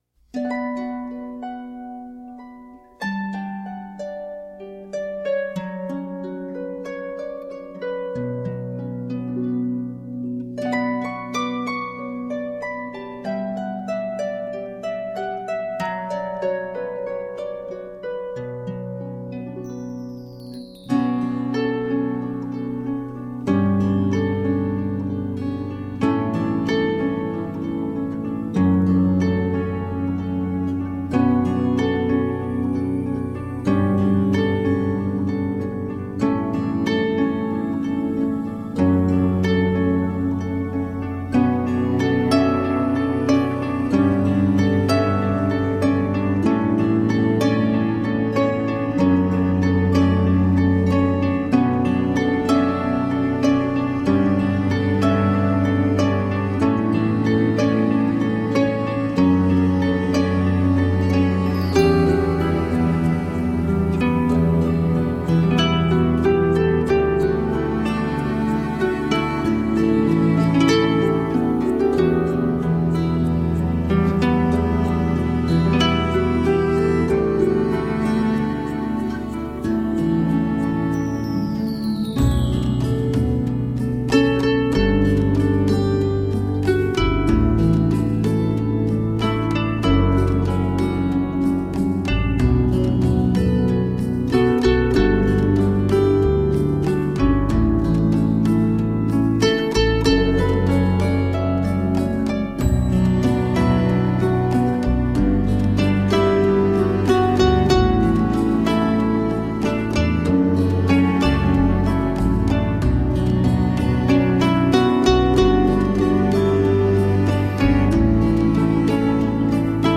Tagged as: World, New Age, Renaissance, Celtic, Harp